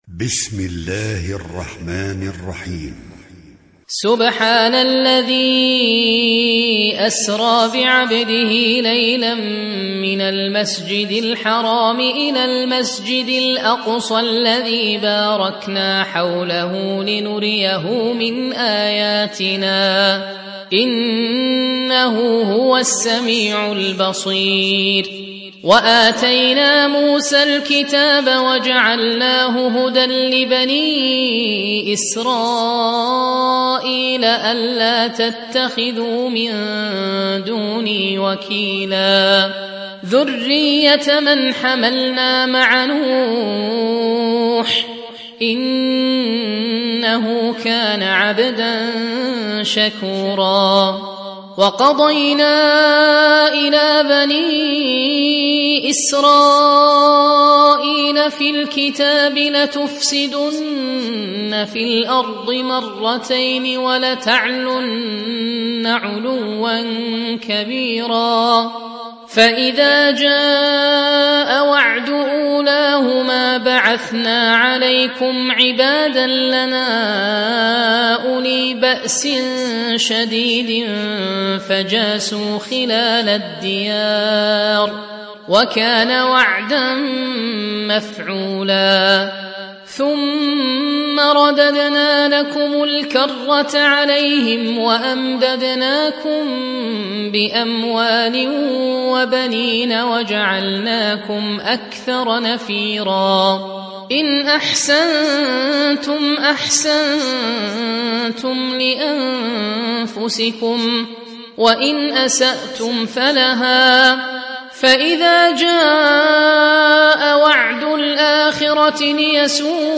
حفص عن عاصم